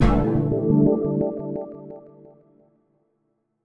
MESSAGE-B_Decline.wav